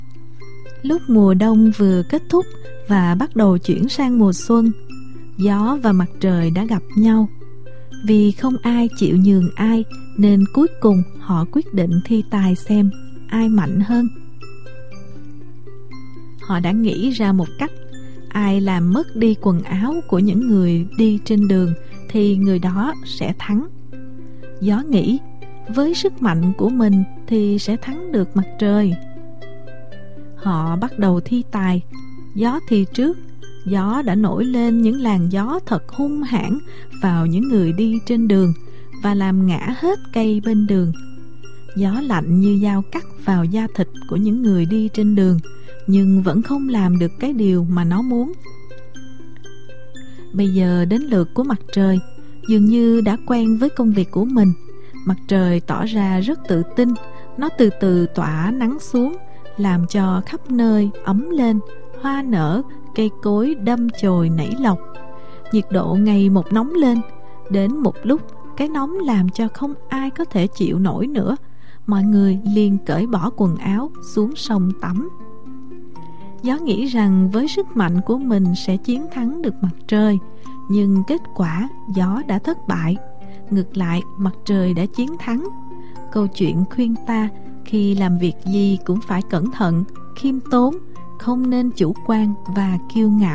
KỂ CHUYỆN - ĐỌC THƠ